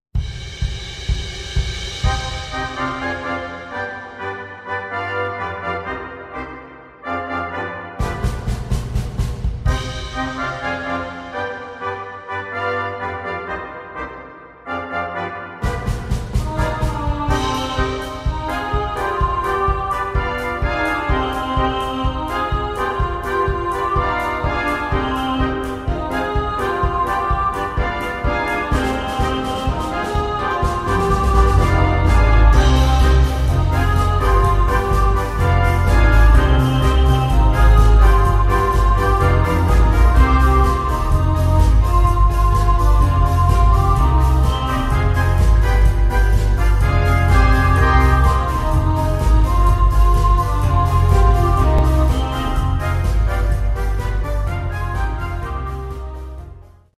Gattung: Big Band mit Gesang
Denn diese Version ist ein vorzüglicher Cha-Cha.